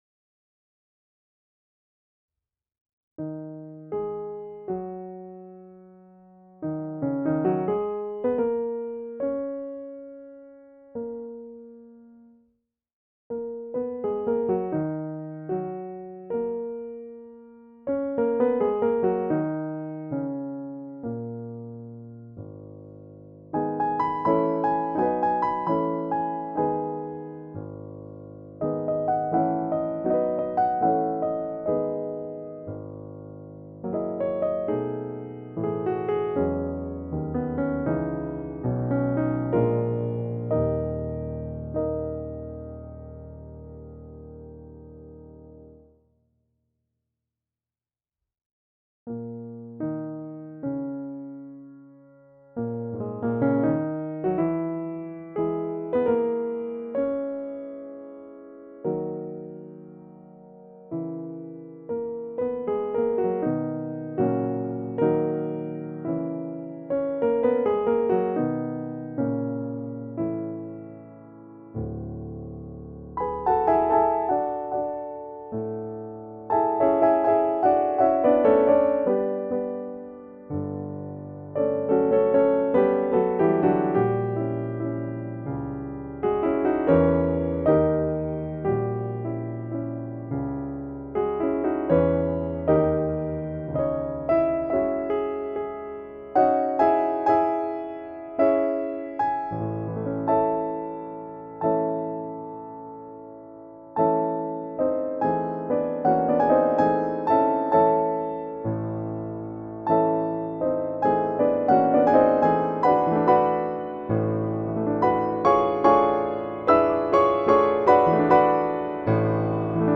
Testbericht – Grotrian Concert Royal grand piano Software von Modartt
Die mit dem für die Pianoteq Software üblichen physical modelling wurde der Grotrian Concert Royal aufgenommen und bearbeitet.